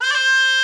D3FLUTE83#07.wav